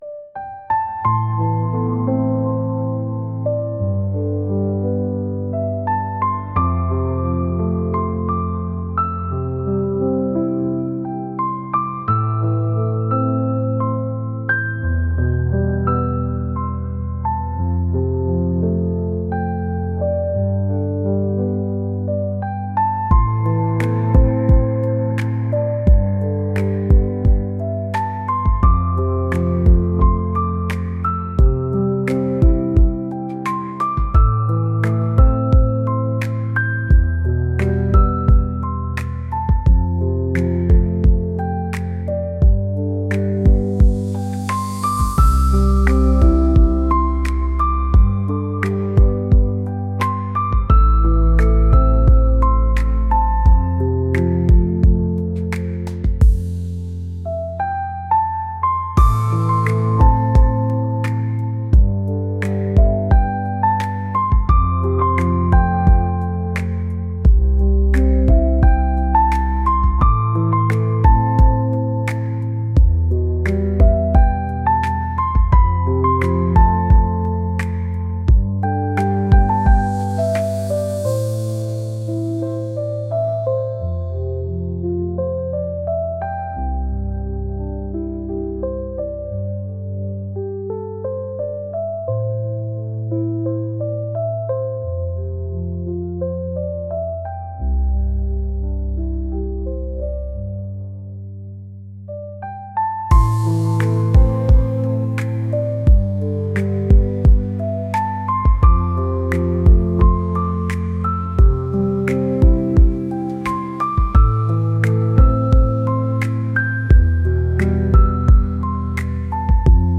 ambient